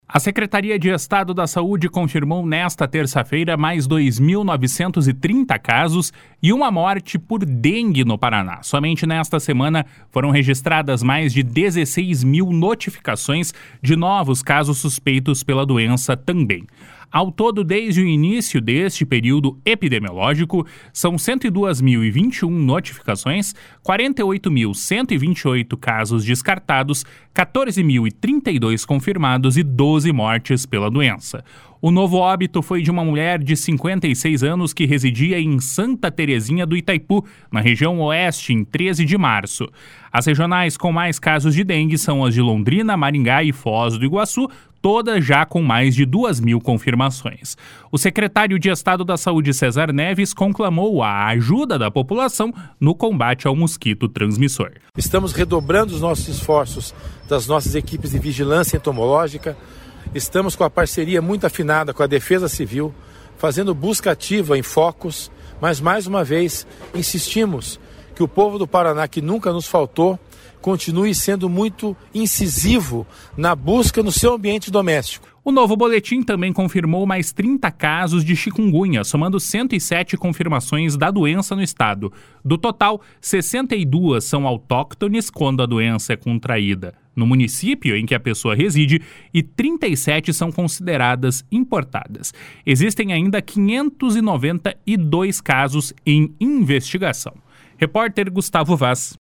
O secretário de Estado da Saúde, César Neves, conclamou a ajuda da população no combate ao mosquito transmissor. // SONORA CÉSAR NEVES //